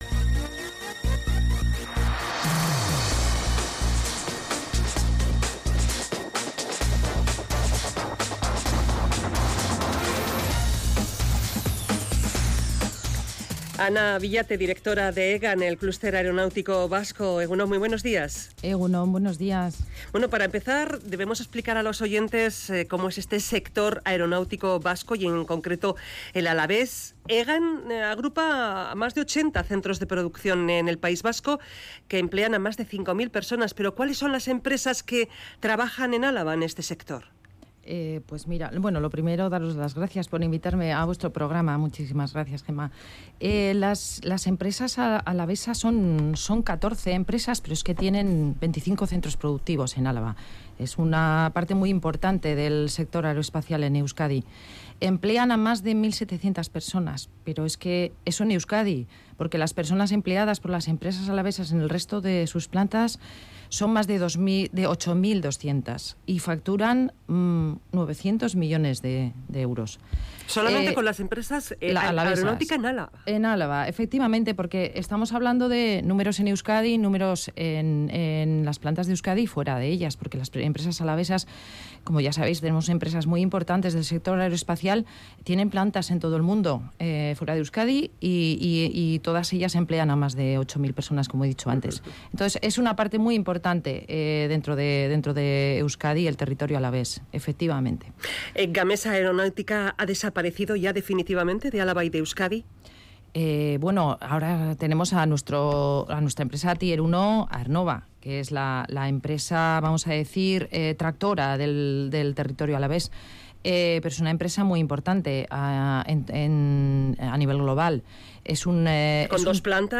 Entrevistada en Radio Vitoria